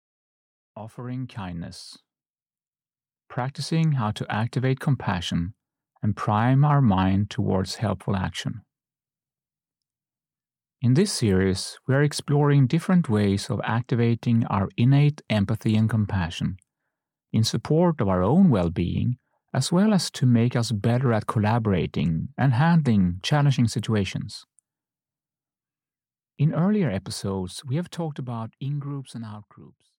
Offering Kindness (EN) audiokniha
Ukázka z knihy